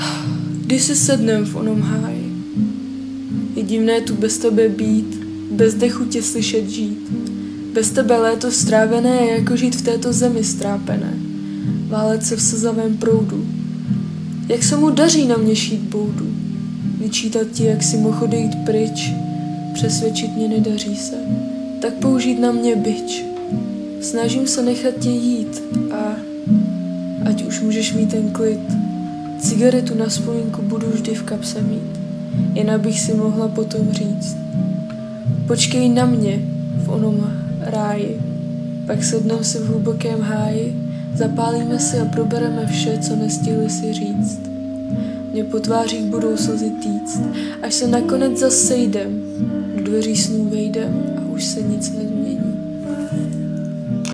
skvěle si to dáváš - styl přednesu a frázování ala rap - s jemných nuancích naléhavosti - už to je druhá, co poslouchám